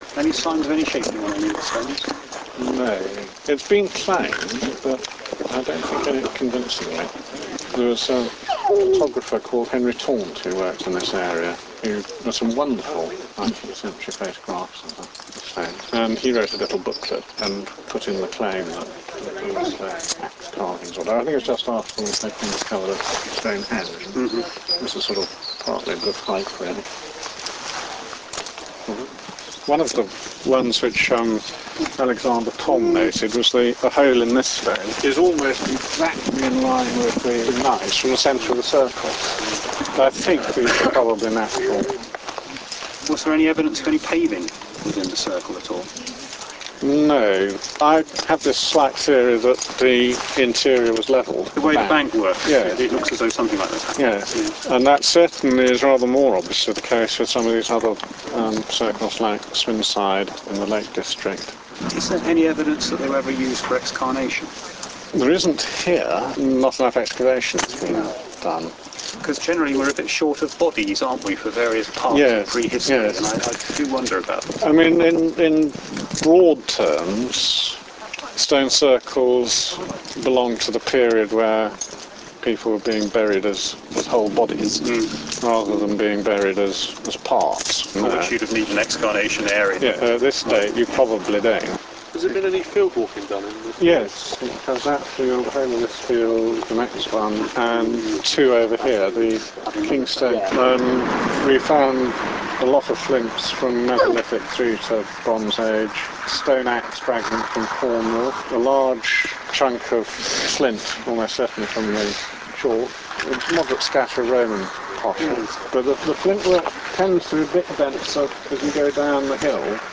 Questions & Heckling :-)
Mini-Disc recorder